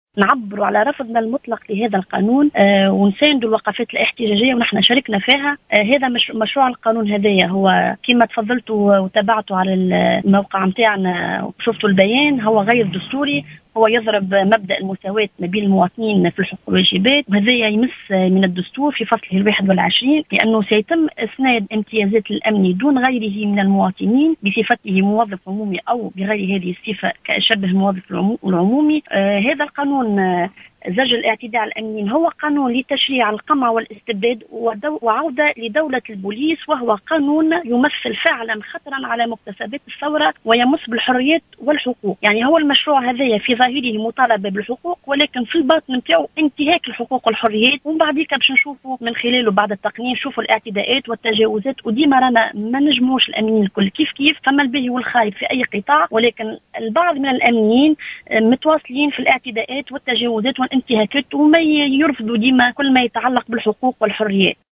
في اتصال هاتفي للجوهرة أف أم